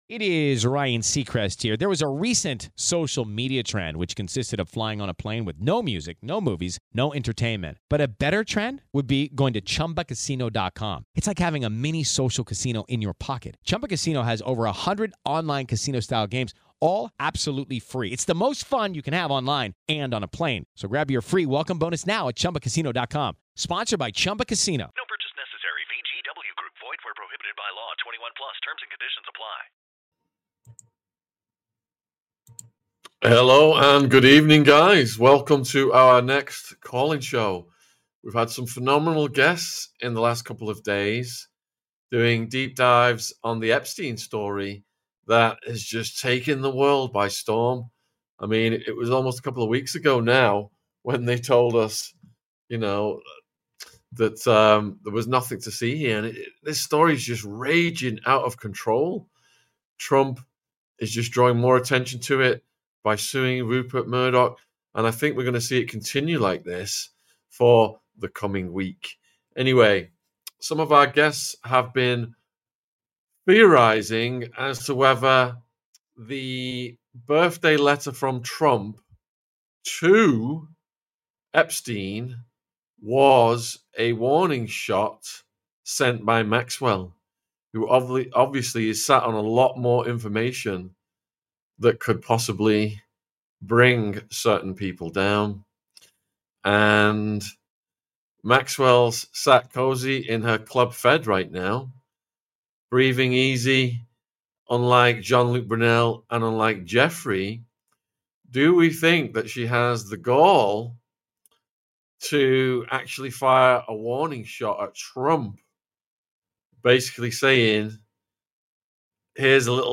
MAXWELL Warning Shot TO TRUMP Call in Show! Epstein Client List & MOSSAD, CIA Intelligence